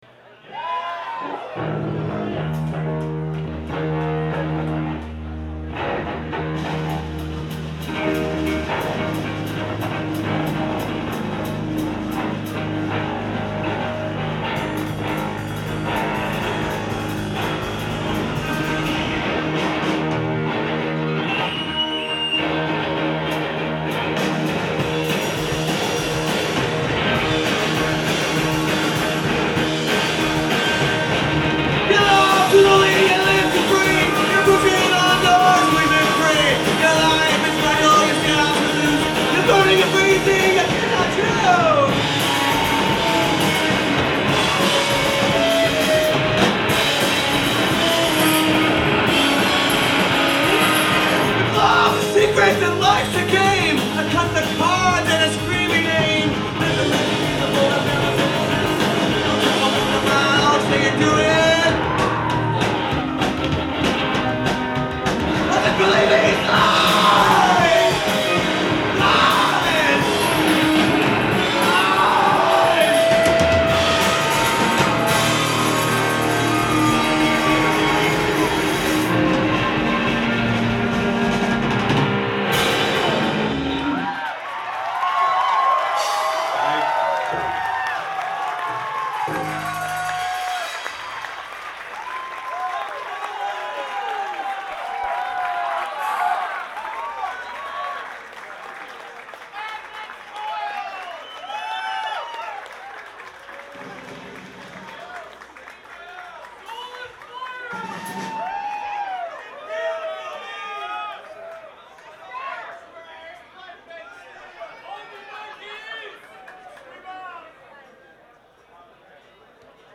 Live at the Paradise
in Boston, Massachusetts